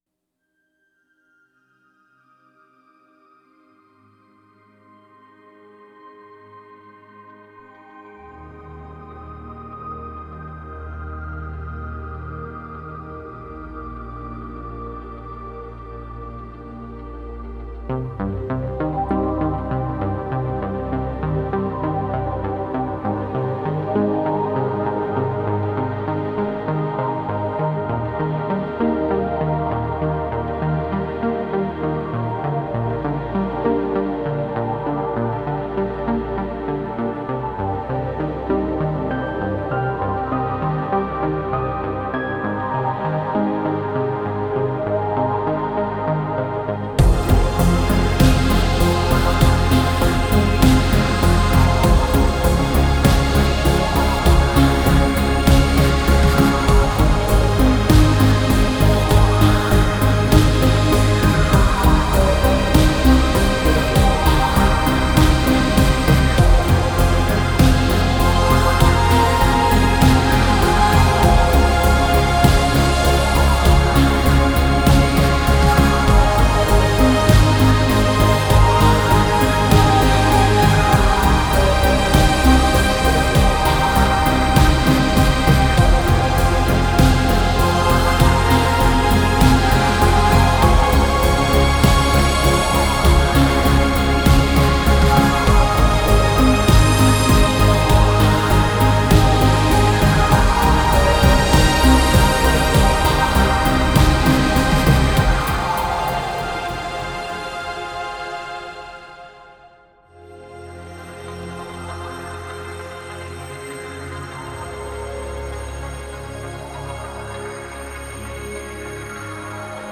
Genre: House , Trance , Spacesynth , Synthpop , Electronic.